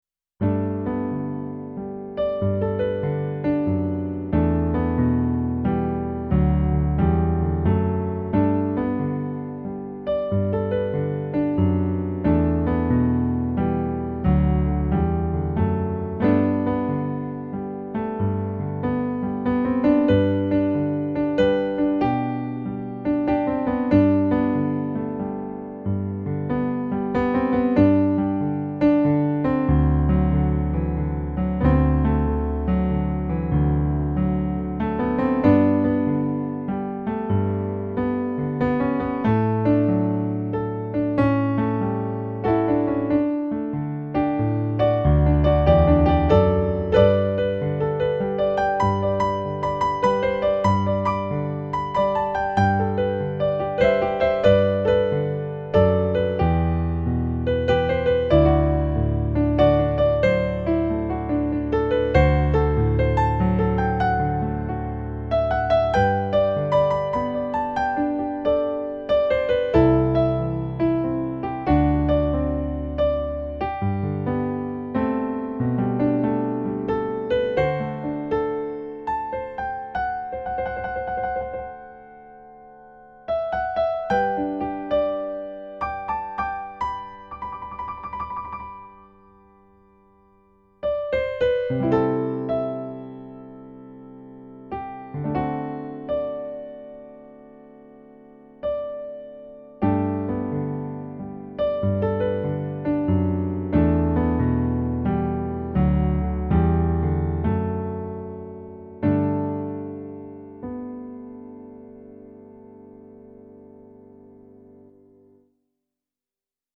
eight piano solo arrangements.  34 pages.
chill remix